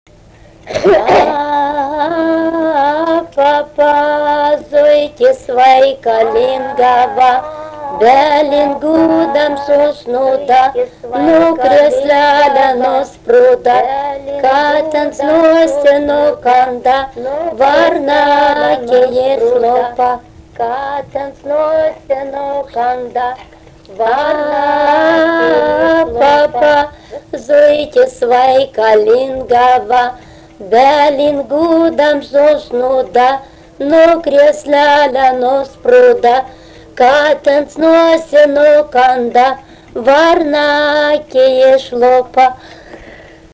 daina vaikų Erdvinė aprėptis Žaduvėnai
Atlikimo pubūdis vokalinis
Du kartus padainuoja